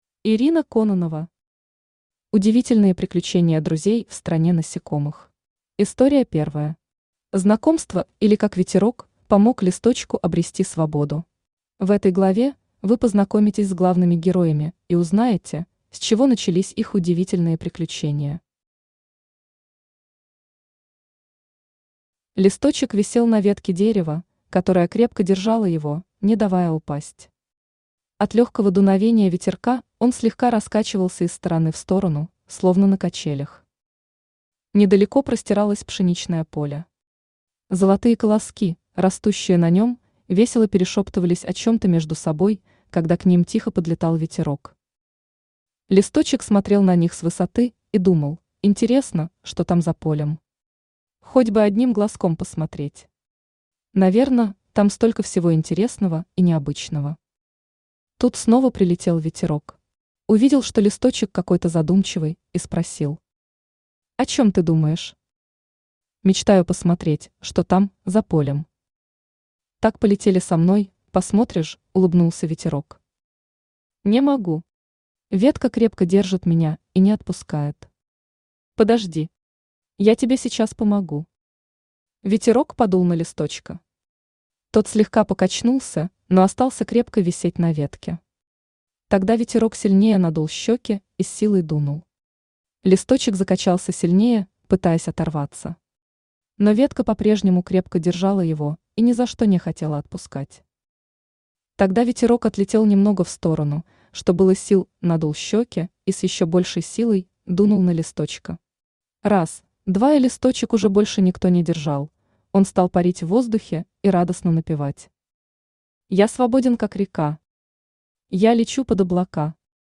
Аудиокнига Удивительные приключения друзей в стране насекомых | Библиотека аудиокниг
Aудиокнига Удивительные приключения друзей в стране насекомых Автор Ирина Кононова Читает аудиокнигу Авточтец ЛитРес.